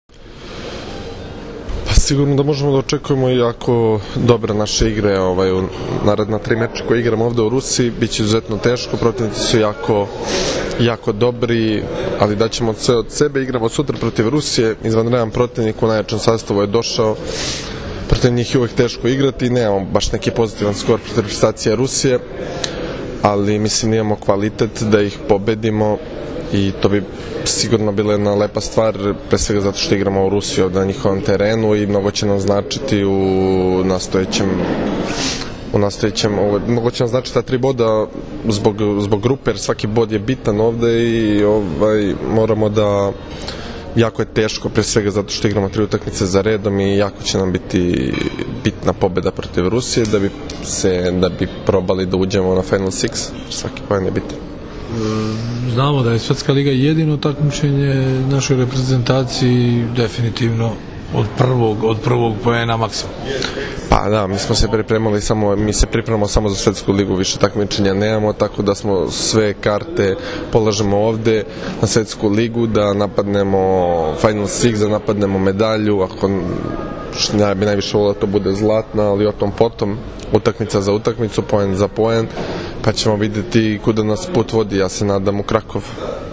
Treneri na konferenciji za novinare – Srbija u petak (19,10) protiv Rusije
IZJAVA UROŠA KOVAČEVIĆA